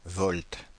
Ääntäminen
Ääntäminen France (Paris): IPA: /vɔlt/ Paris: IPA: [vɔlt] Haettu sana löytyi näillä lähdekielillä: ranska Käännös Konteksti Substantiivit 1. volt fysiikka, sähkö, metrologia Suku: m .